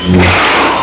saber02.wav